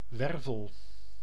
Ääntäminen
IPA: [vɛʁ.tɛbʁ]